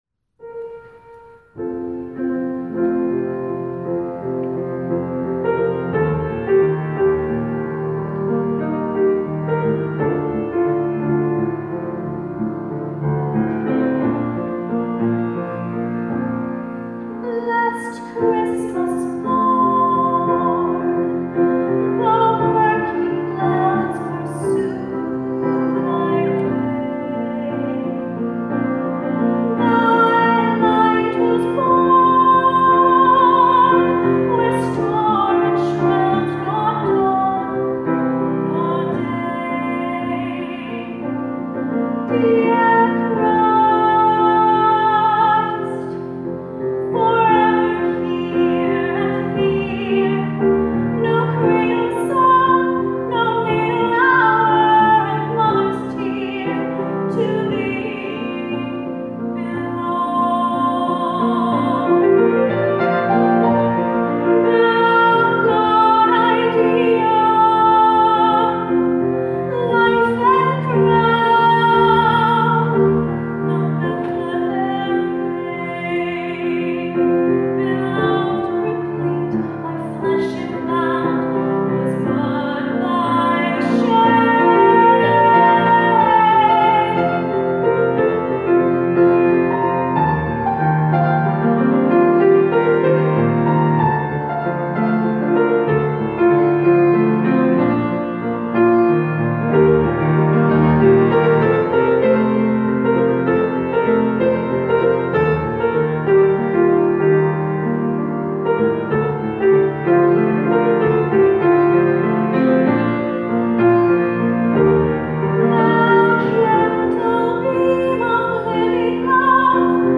You can listen below to our cell phone recording of the solo from the evening service that day, which was held in the original edifice.
organ